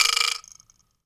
pgs/Assets/Audio/Comedy_Cartoon/wood_vibraslap_hit_04.wav at master
wood_vibraslap_hit_04.wav